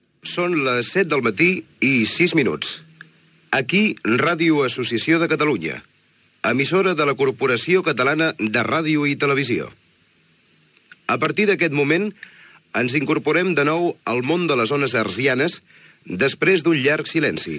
Hora, indentificació i incii de les emissions regulars
FM